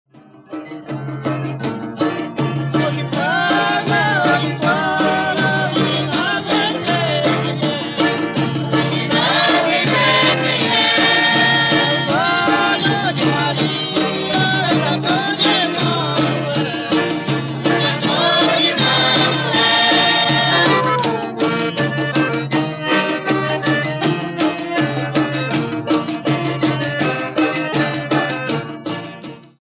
Folguedo natalino em que grupos cantam e dançam, em geral, na véspera do Dia de Reis. De origem ibérica, chegou ao Brasil durante o período colonial e apresenta versões variadas em todo o país.
Os instrumentos que acompanham o grupo são violão, sanfona, pandeiro, zabumba, triângulo e ganzá.
Reisado
reisado.mp3